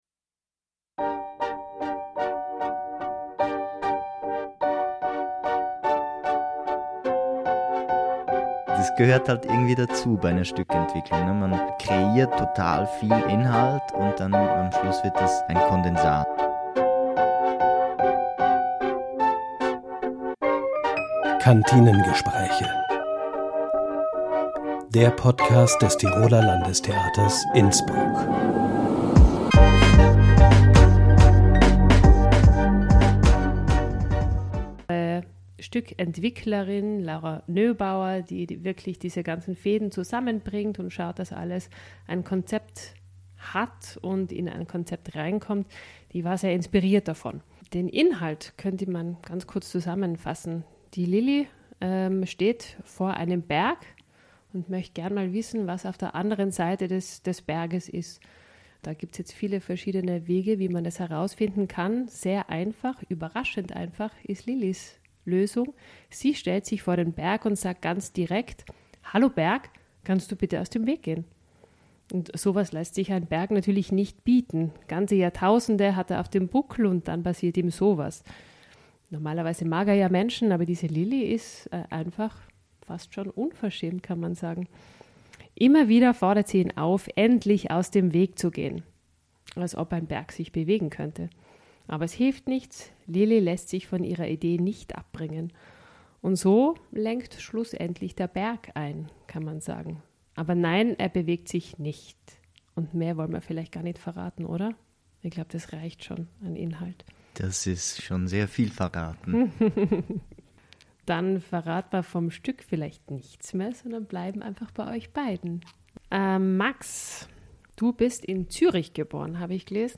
Dramaturgin